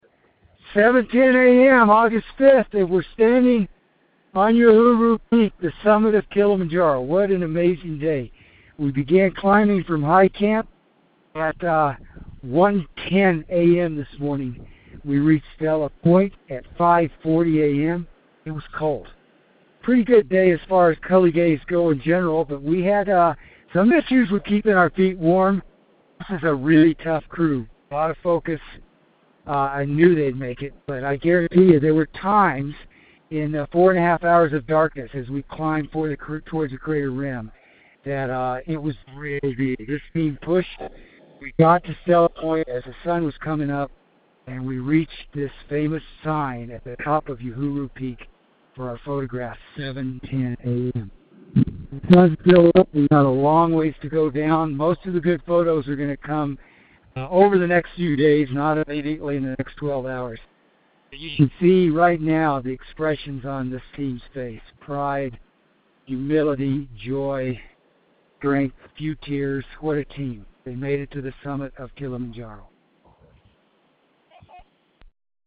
Kilimanjaro Dispatch: August 5, 2016 – Calling from the Summit of Kilimanjaro